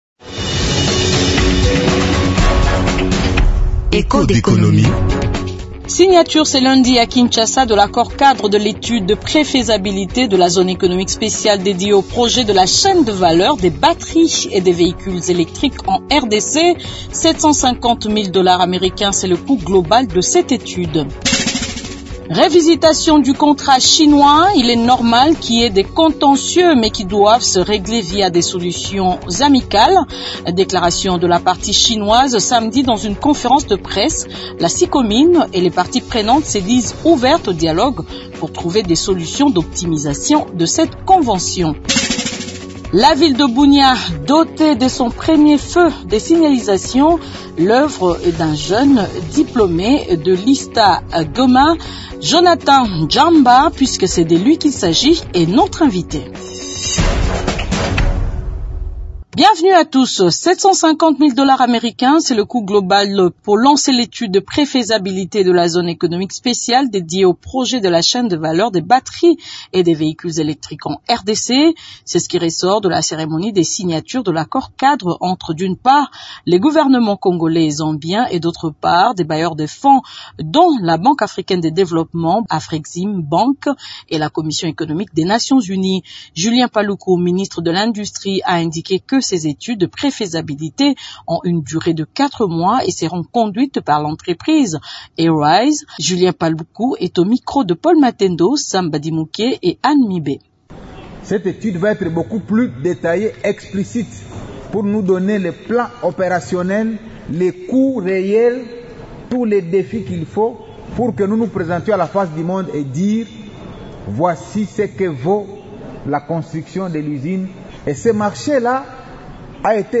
Ce sont là, les sujets du jour de ce magazine de l'économie.